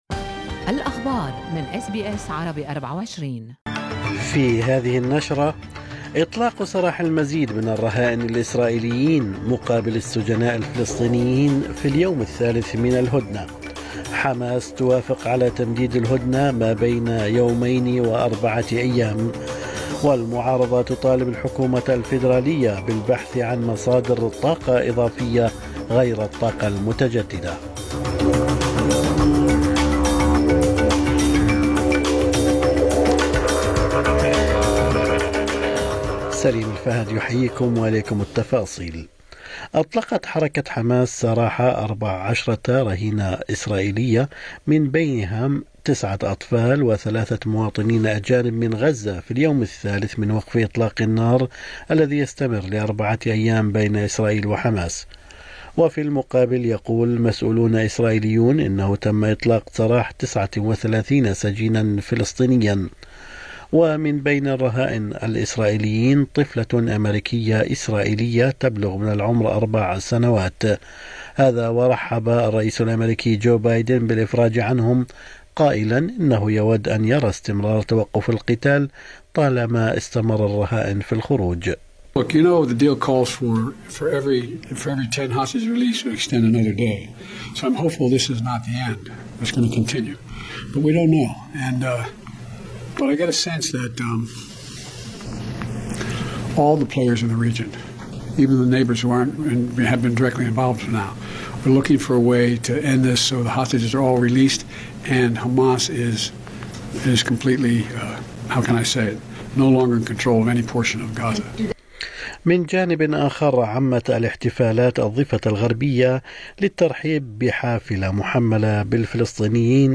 نشرة اخبار الصباح 27/11/2023